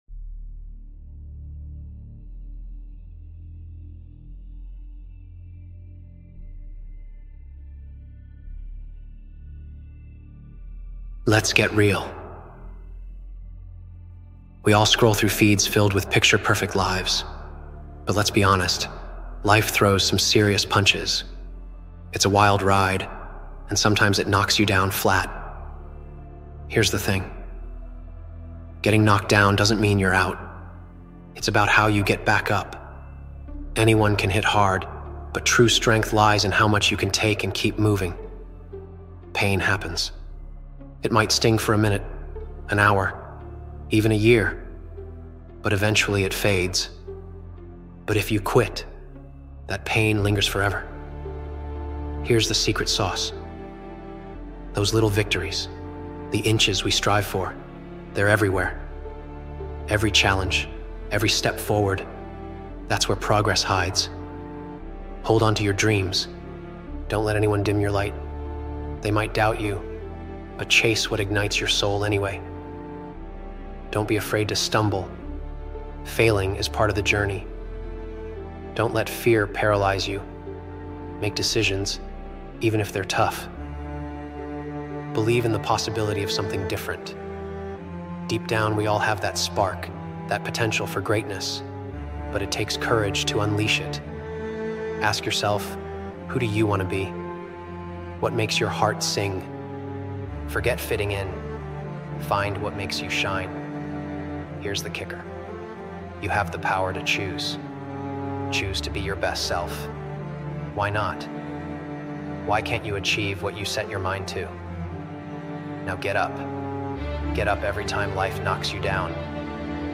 Quit Staying Average | Yoichi Isagi Motivational Speech